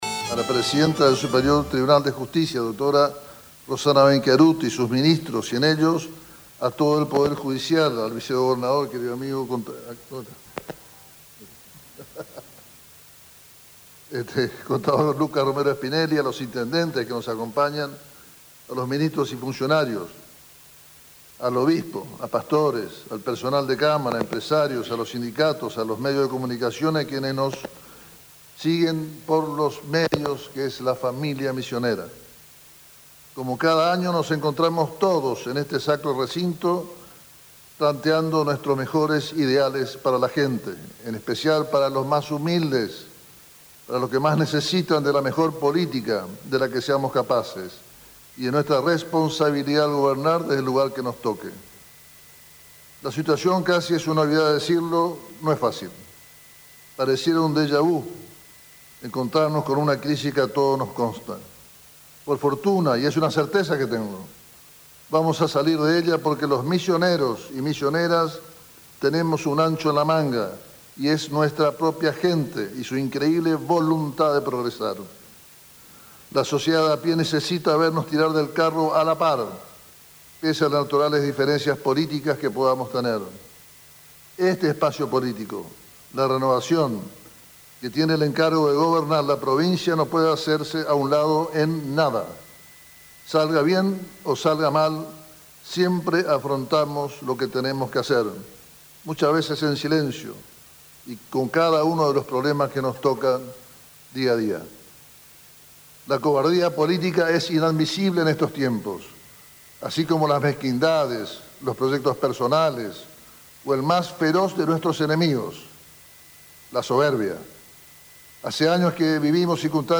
El discurso completo del gobernador Passalacqua en la apertura de sesiones de la Cámara de Representantes